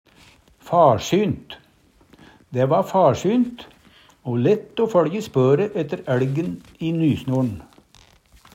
DIALEKTORD PÅ NORMERT NORSK farsynt det er lett å sjå spor etter dyr og mennesker i snø eller myr Eksempel på bruk Dæ va farsynt o lett o føLje spøre ætte æLgen i nysnor'n. Hør på dette ordet Ordklasse: Adverb Attende til søk